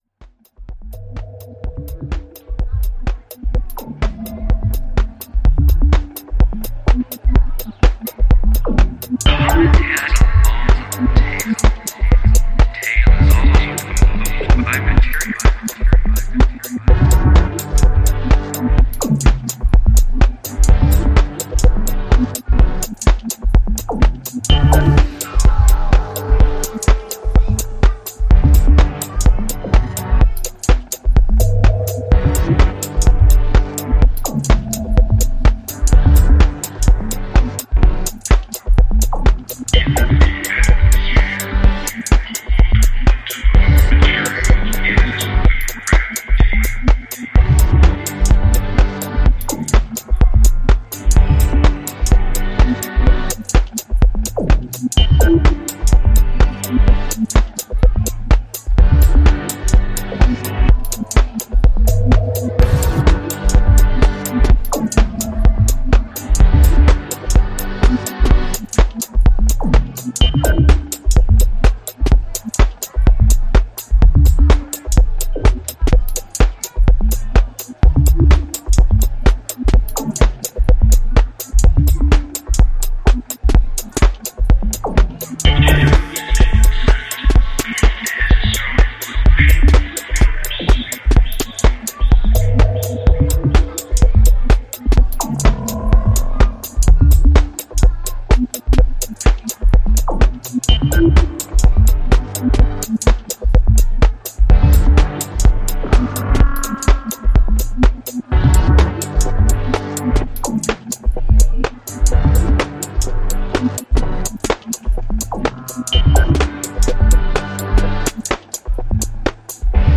supplier of essential dance music
Electronix House